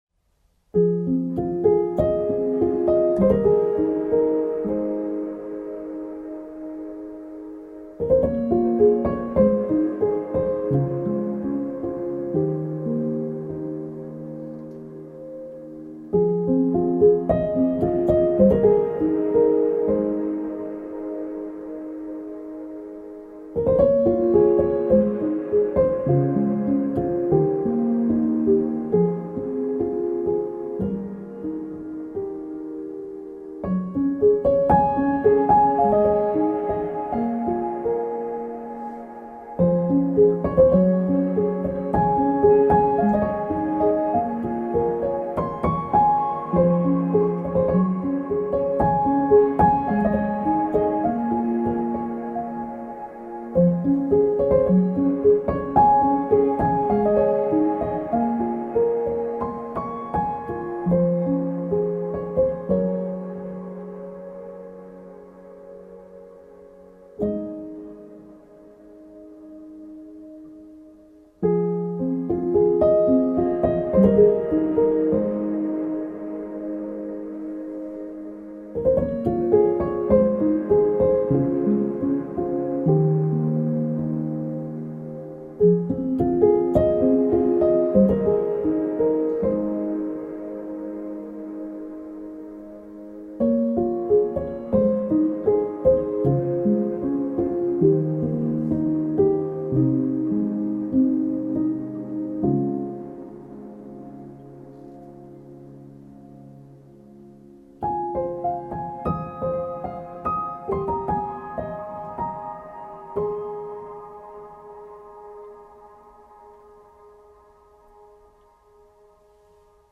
موسیقی بی کلام آرامش بخش پیانو عصر جدید
موسیقی بی کلام پیانو